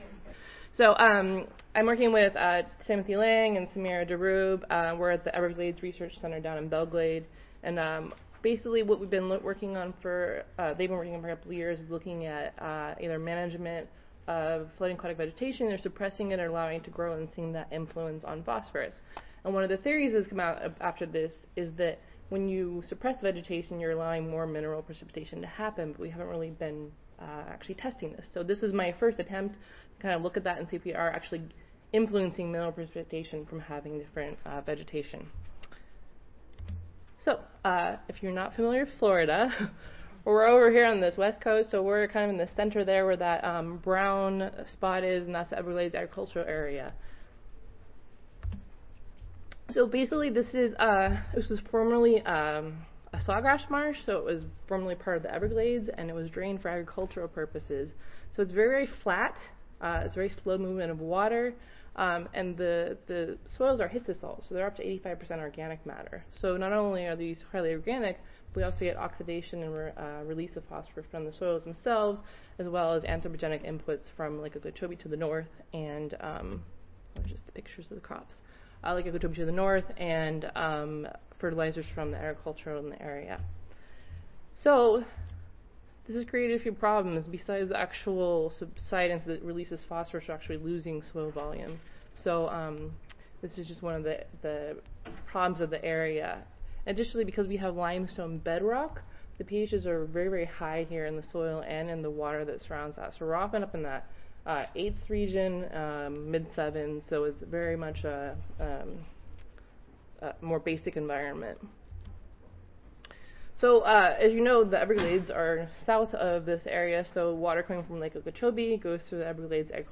University of Florida Audio File Recorded Presentation